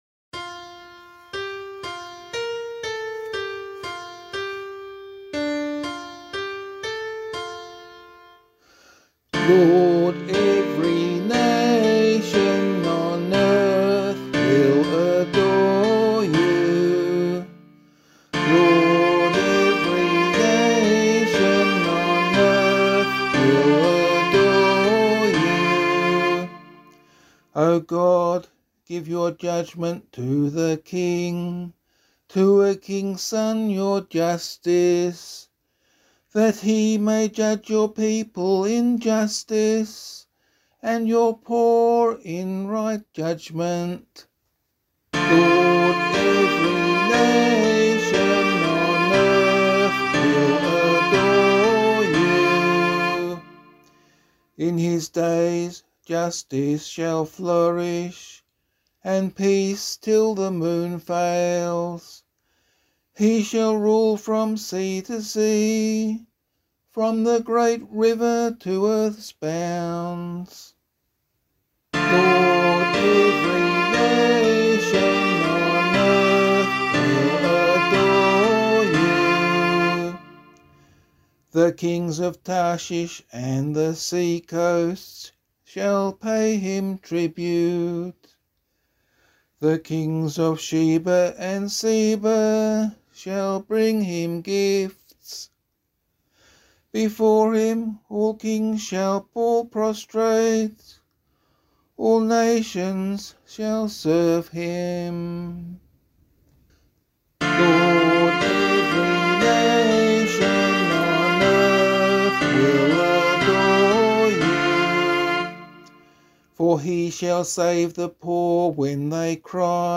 010 Epiphany Psalm [LiturgyShare 1 - Oz] - vocal.mp3